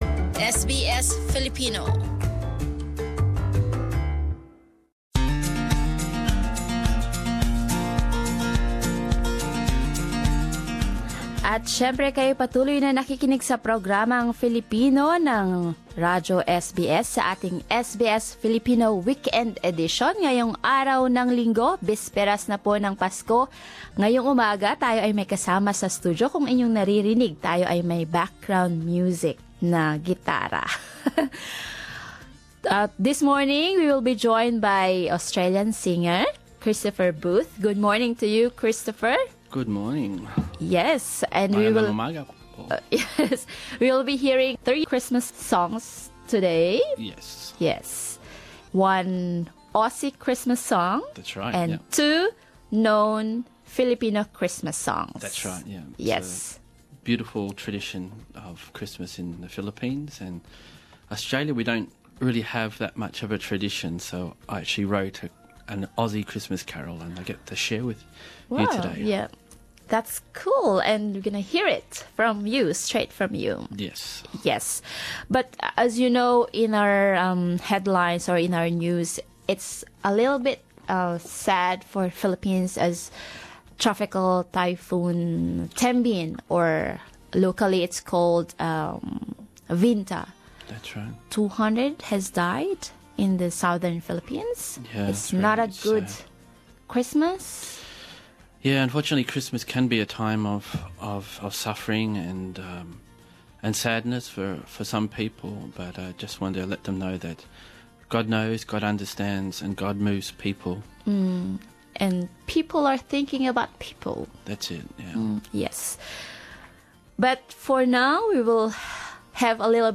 And he shares what he loves about Filipinos' celebration of Christmas and sings us some Christmas songs including one of his originals.